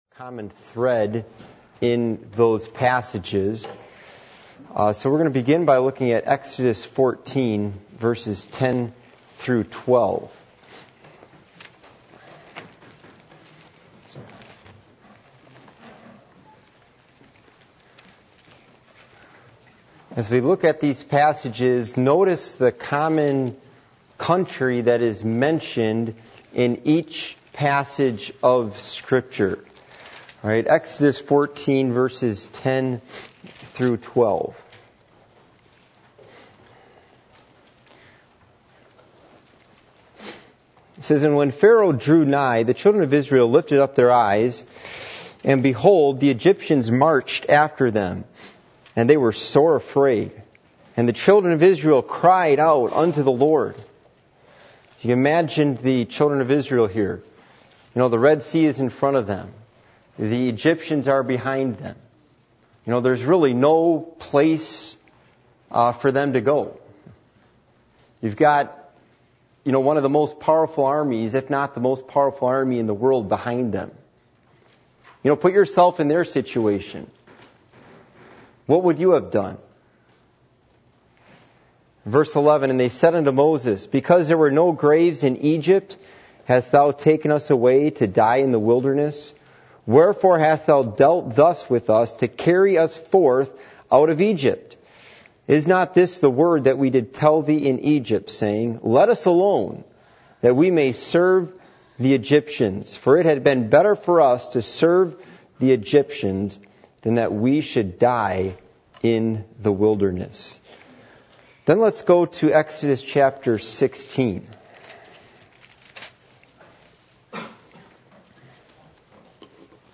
Passage: Exodus 16:1-3 Service Type: Midweek Meeting